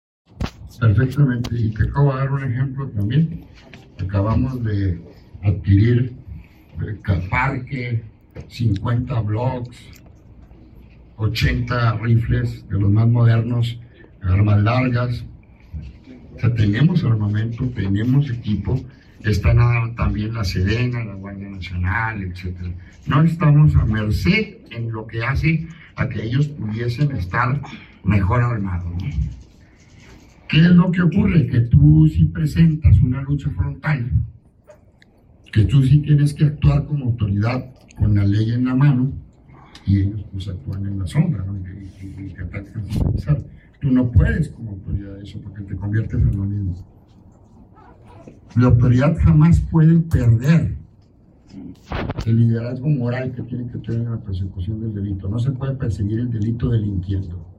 AUDIO: CÉSAR JÁUREGUI MORENO, FISCAL GENERAL DEL ESTADO